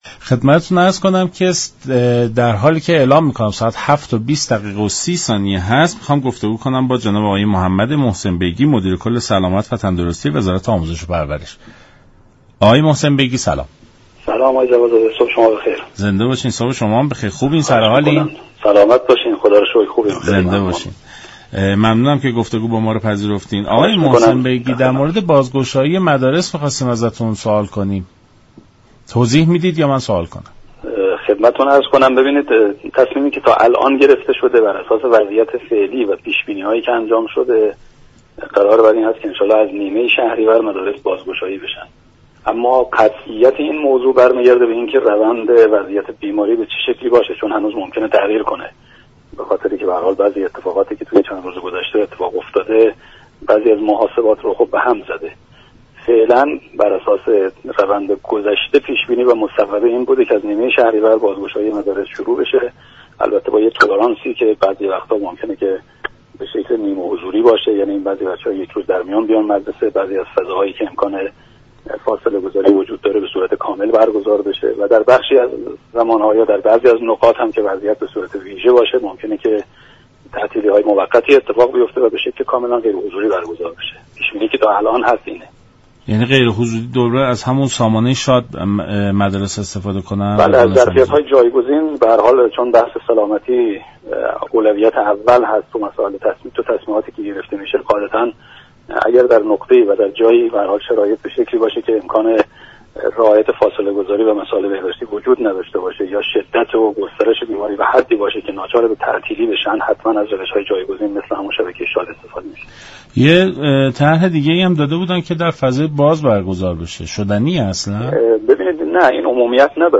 به گزارش شبكه رادیویی ایران، «محمد محسن بیگی» مدیركل دفتر سلامت و تندرستی وزارت آموزش و پرورش در برنامه «سلام صبح بخیر» رادیو ایران درباره بازگشایی زود هنگام مدارس گفت: تصمیم اخیر دولت برای بازگشایی دوباره مدارس بر اساس پیش بینی ها و شرایطی فعلی كشور بوده است، قطعیت این موضوع به روند شیوع بیماری كرونا بستگی دارد.